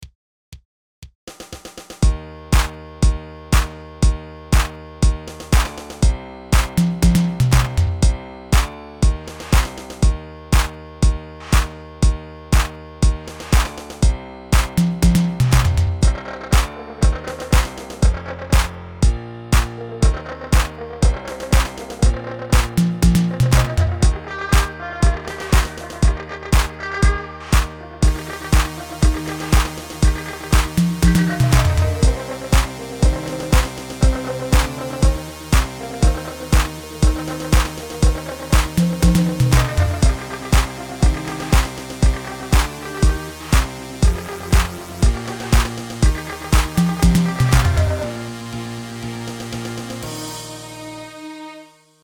軽快なビートに乗せたドラム、シンセサイザー、そしてピアノの組み合わせが特徴です。
シンプルながらも洗練されたサウンド。